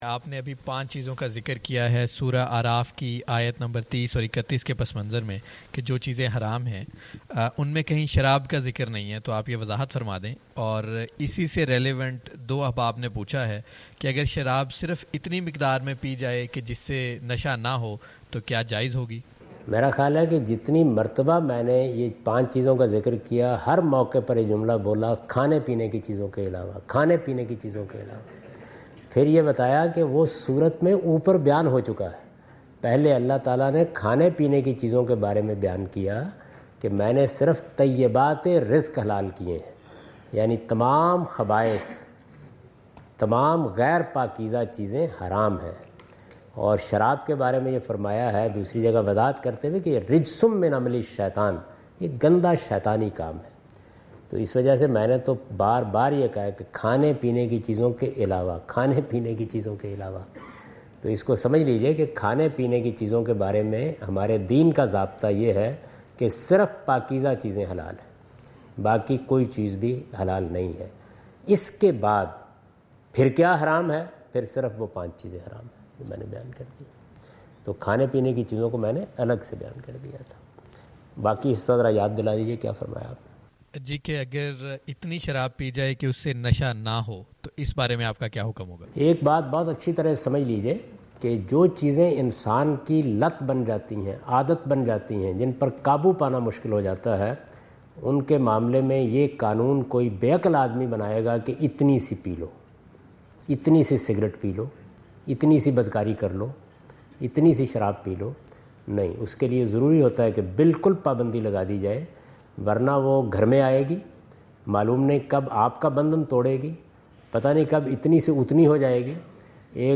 Category: English Subtitled / Questions_Answers /
Javed Ahmad Ghamidi answer the question about "prohibition of alcohol consumption" in Macquarie Theatre, Macquarie University, Sydney Australia on 04th October 2015.
جاوید احمد غامدی اپنے دورہ آسٹریلیا کے دوران سڈنی میں میکوری یونیورسٹی میں "شراب نوشی کی ممانعت" سے متعلق ایک سوال کا جواب دے رہے ہیں۔